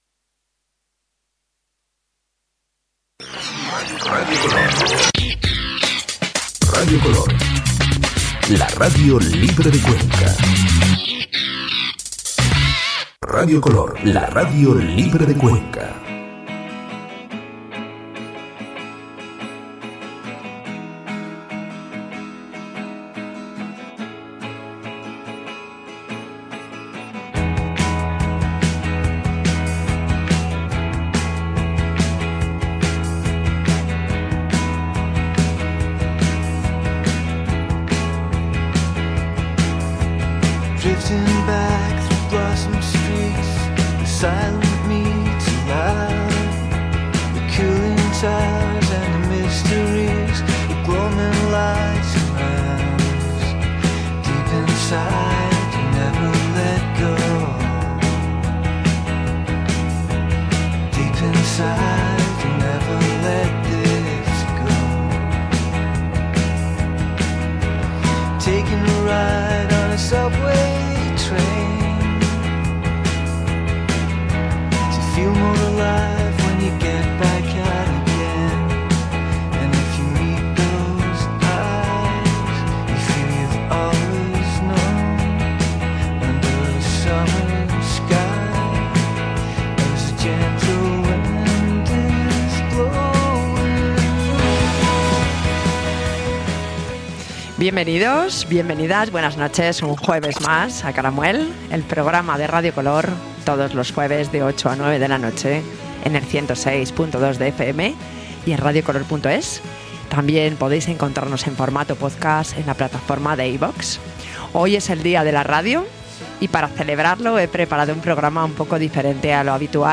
Celebramos en directo el Día Mundial de la Radio.
En este podcast escuchamos música desde los primeros años 20 hasta el Bebop de los 40 en Nueva York y algunos fragmentos sonoros.